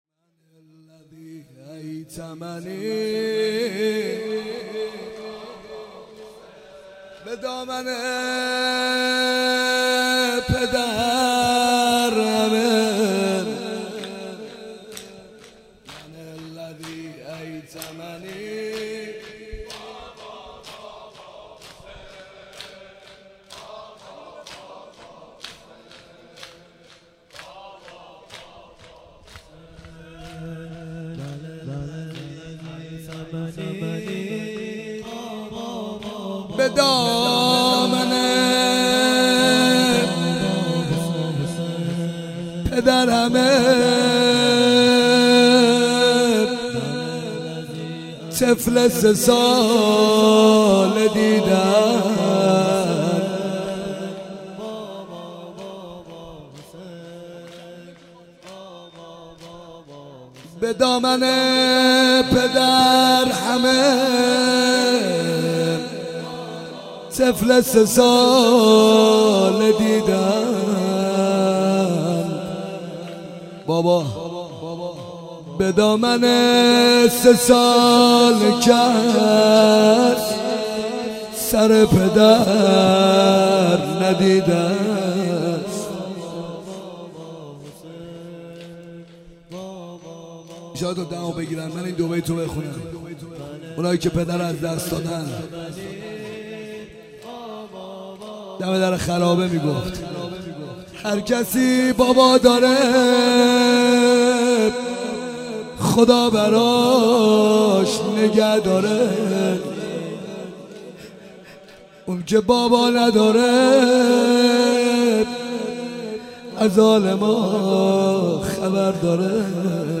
حسینیه کربلا
زمزمه سینه زنی - شب سوم محرم 1398